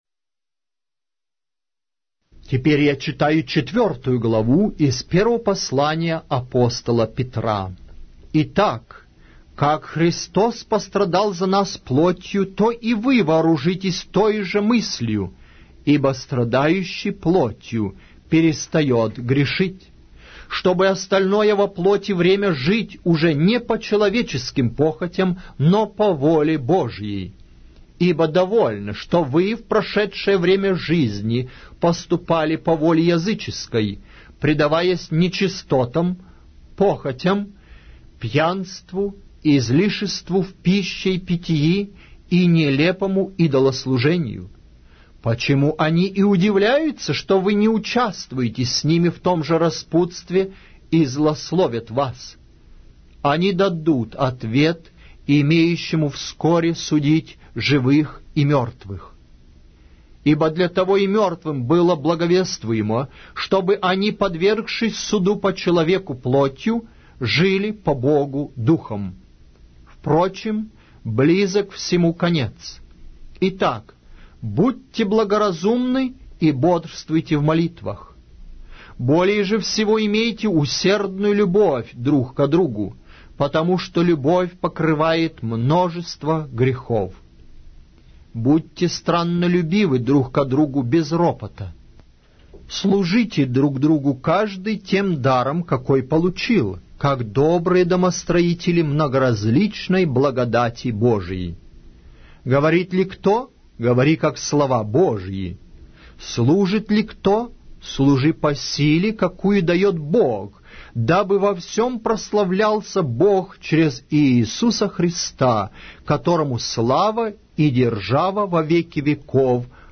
Аудиокнига: 1-е послание Апостола Петра